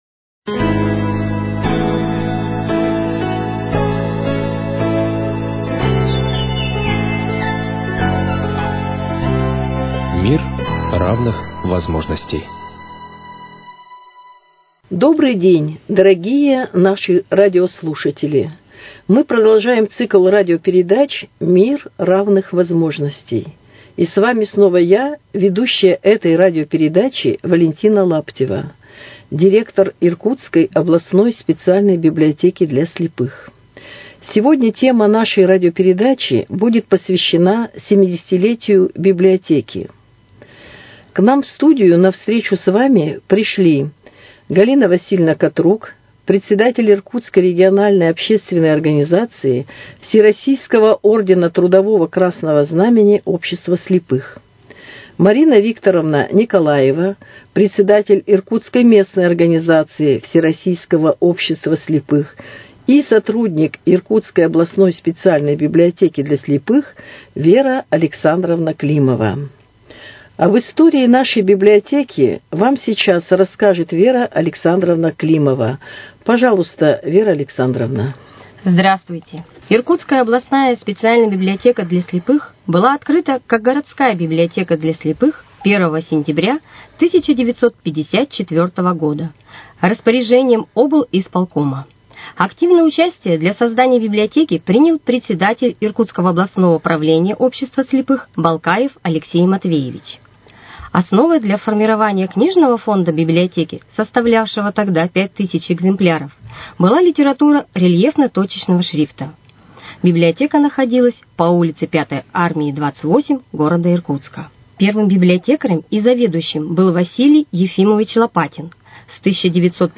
О семидесятилетнем юбилее Иркутской специальной библиотеки для слепых в студии радиоканала